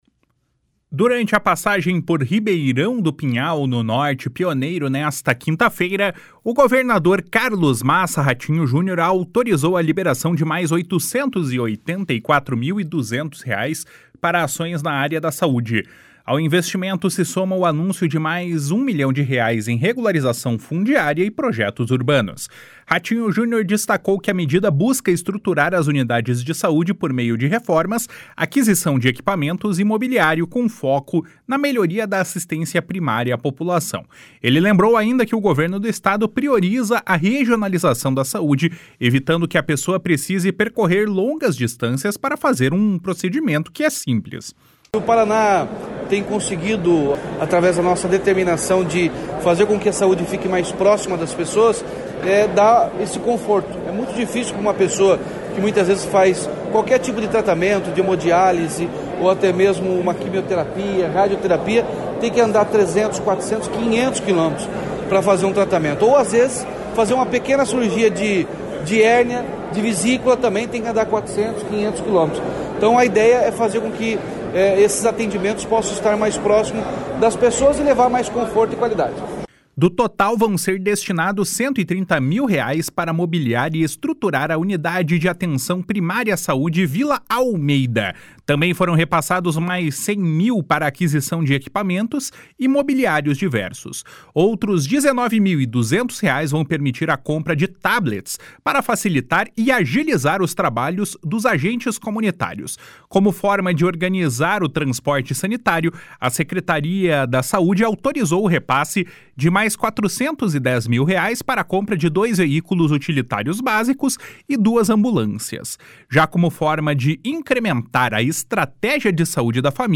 //SONORA RATINHO JUNIOR//